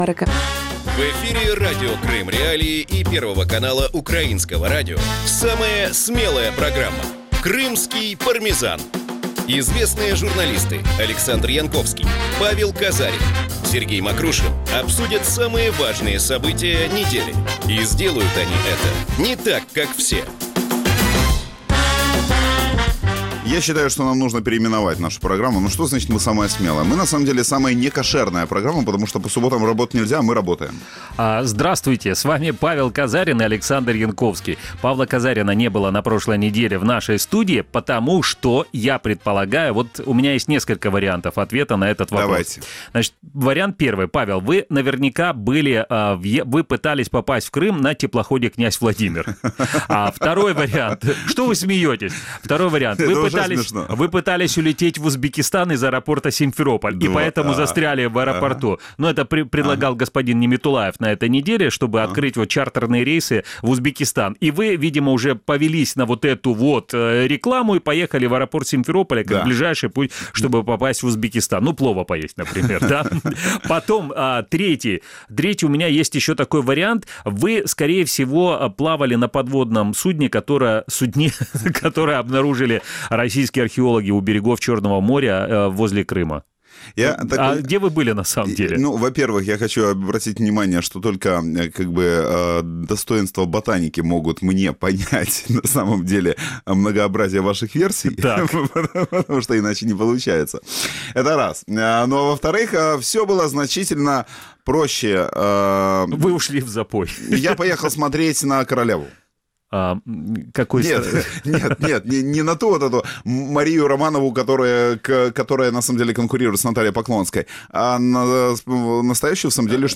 Программу можно слушать в Крыму на Радио Крым.Реалии (105.9 FM), на средних волнах (549 АМ), а также на сайте Крым.Реалии.